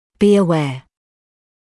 [biː ə’weə][биː э’уэа]знать, быть осведовмленным, обладать определенной информацией (be aware of); осознавать, отдавать себе отчёт